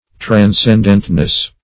Transcendentness \Tran*scend"ent*ness\, n.
transcendentness.mp3